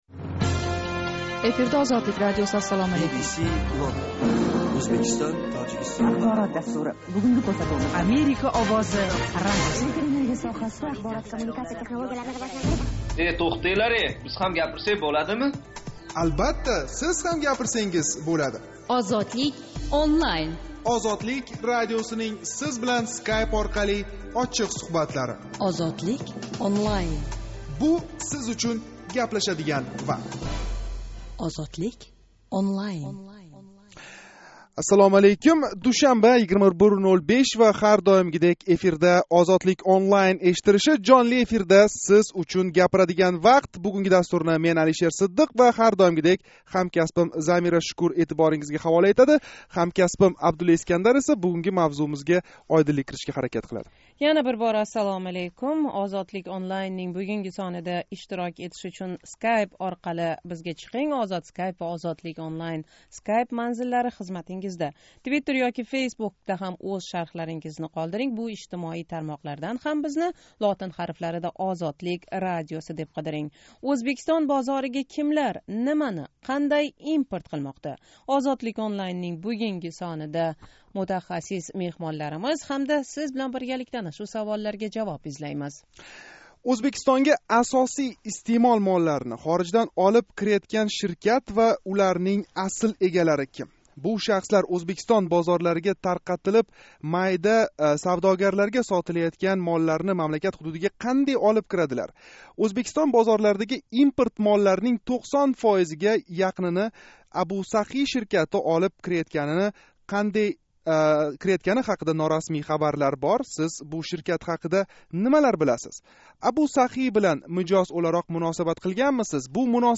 Тошкент вақти билан соат 21:05 да бошланган жонли¸ интерактив мулоқотимизда соҳа мутахассислари¸ савдогарлар, тадбиркорлар билан суҳбатлашдик.